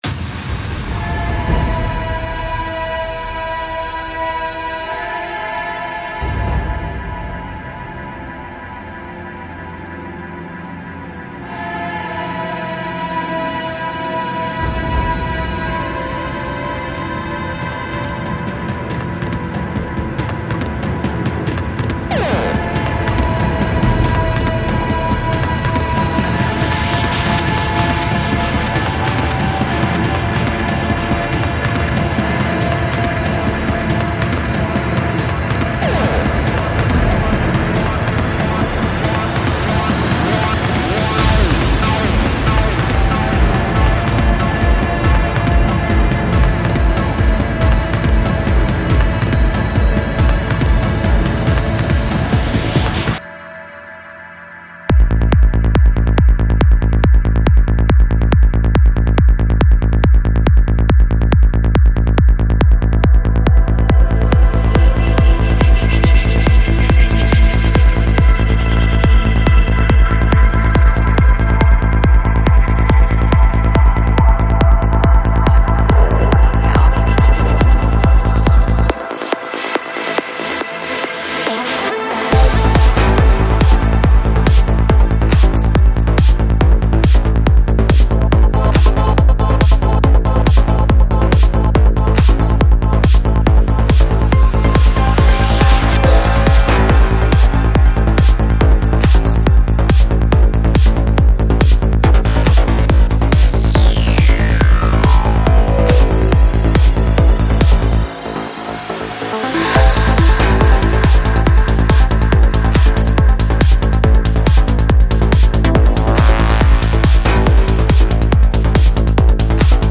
Жанр: Trance
22:16 Альбом: Psy-Trance Скачать 7.73 Мб 0 0 0